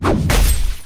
melee-hit-4.ogg